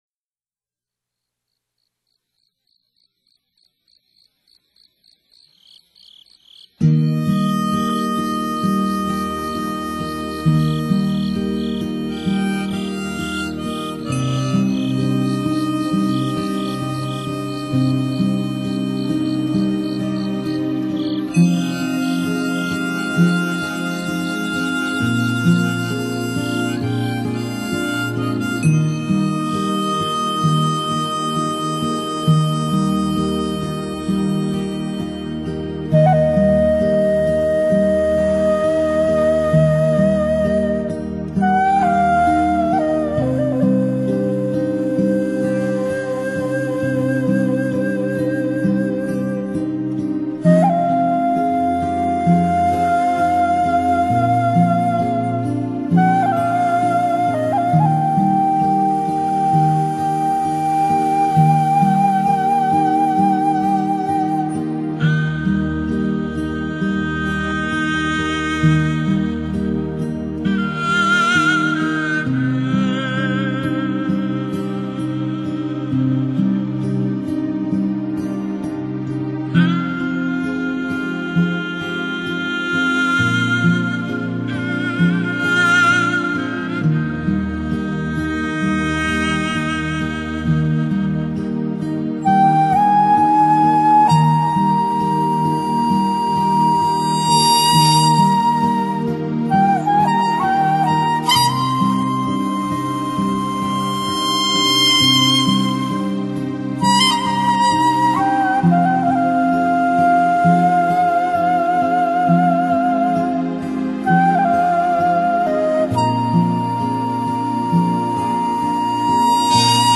天籁一样净化心灵的音乐
音乐类型：Folk
韩国本土音乐一般都是舒缓、流畅的曲子，